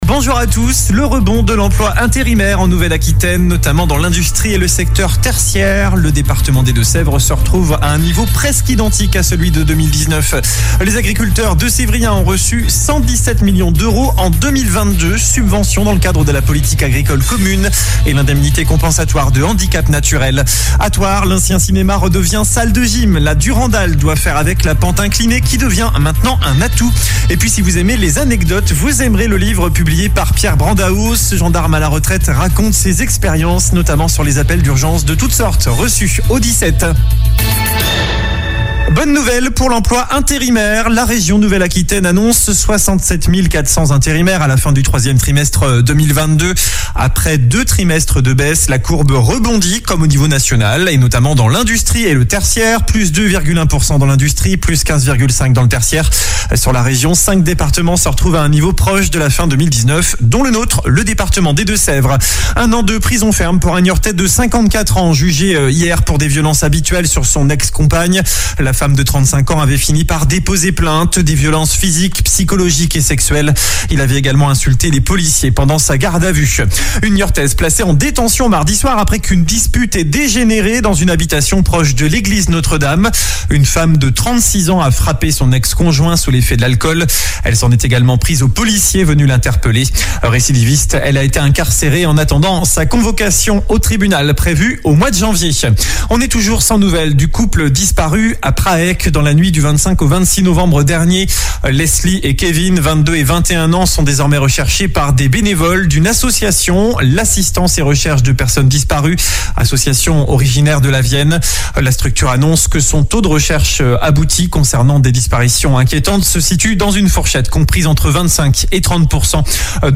Journal du jeudi 29 décembre